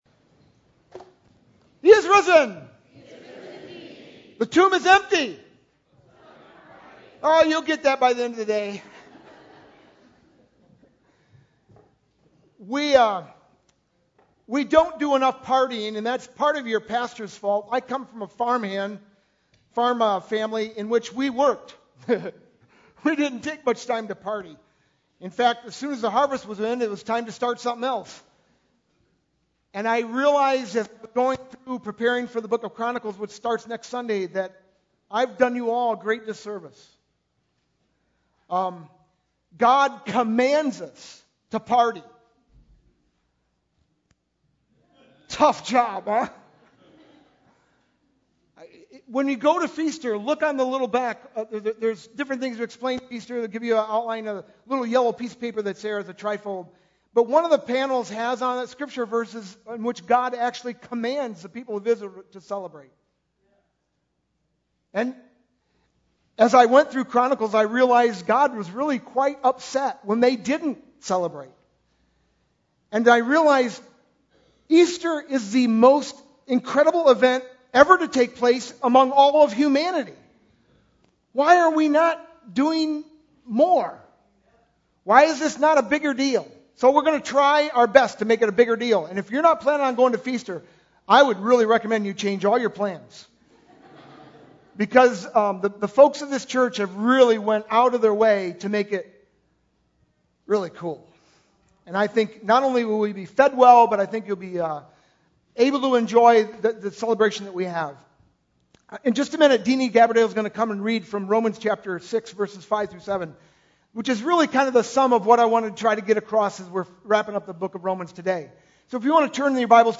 Sunday, April 8th, 2012 (Easter)
sermon-4-8-12.mp3